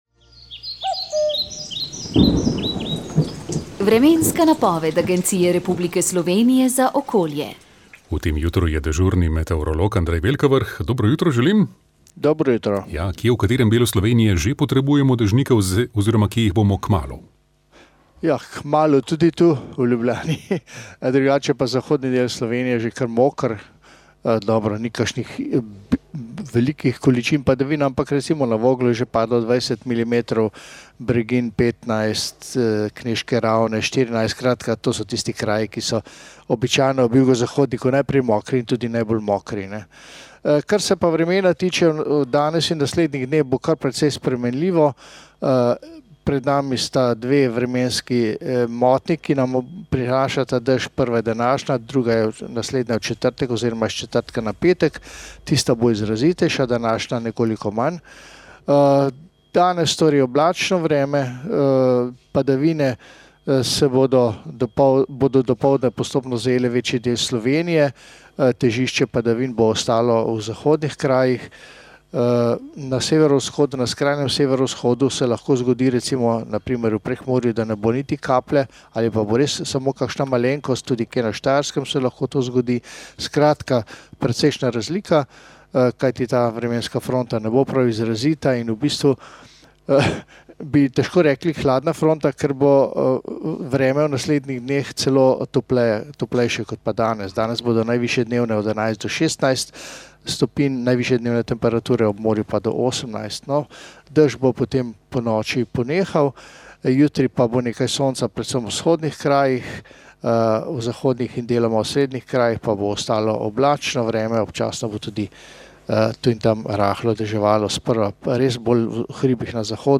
Duhovni nagovor